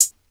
Percs (30).wav